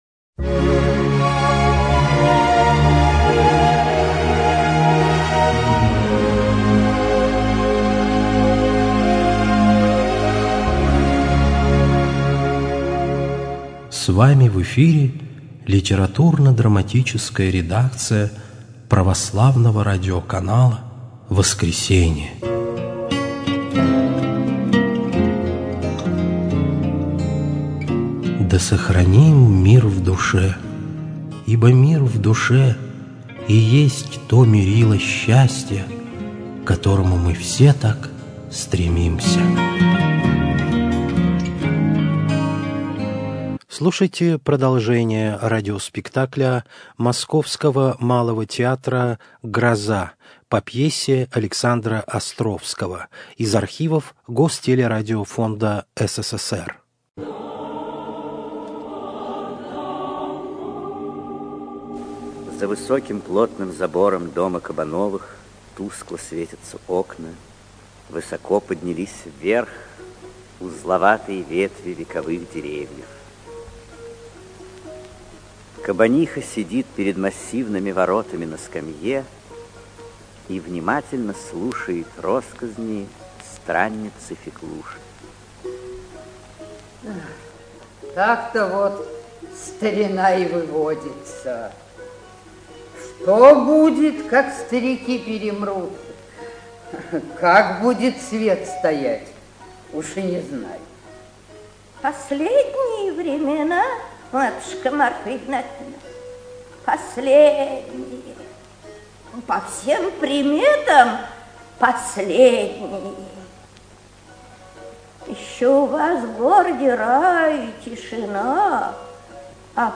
Радиоспектакль по пьесе Островского "Гроза" ч. 3
radiospektakl_po_pese_ostrovskogo_roza_ch_3.mp3